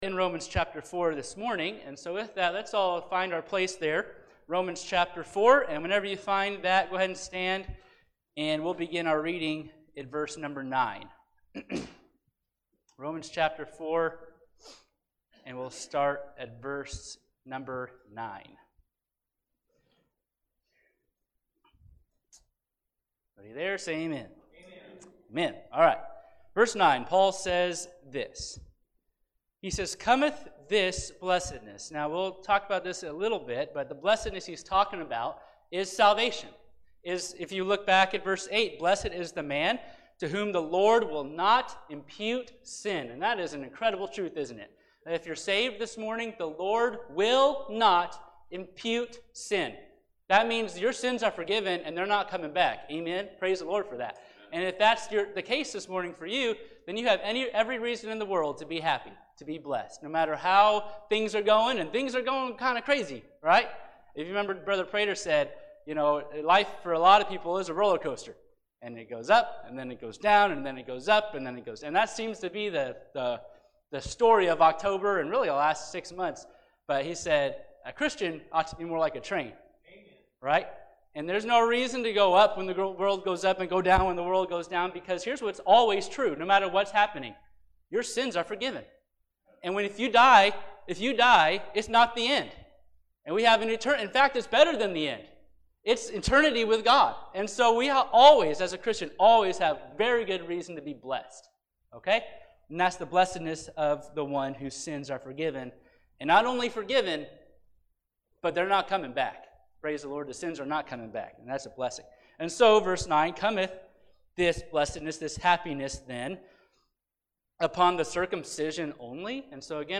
Romans (Sunday School)